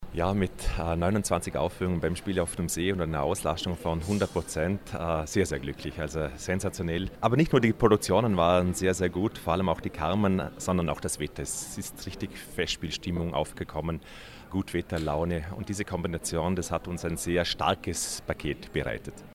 O-Ton Pressekonferenz Vorläufige Bilanz - News